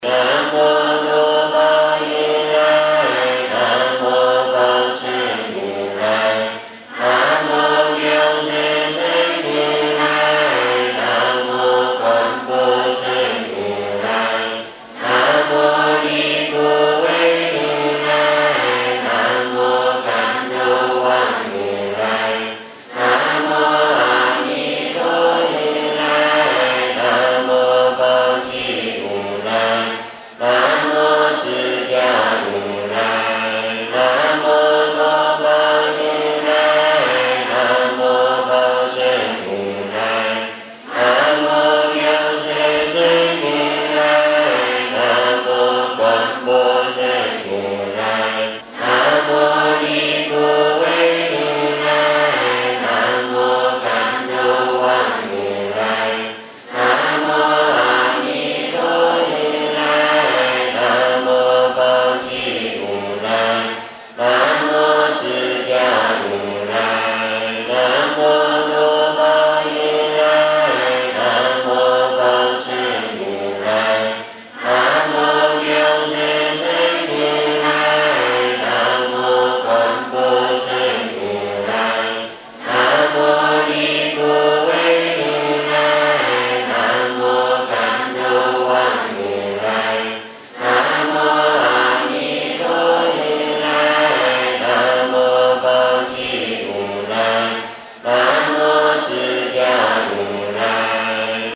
诵经
佛音 诵经 佛教音乐 返回列表 上一篇： 拜愿 下一篇： 普贤十大愿 相关文章 3.重新认识世间的价值--释星云 3.重新认识世间的价值--释星云...